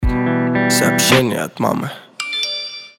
гитара
короткие
голосовые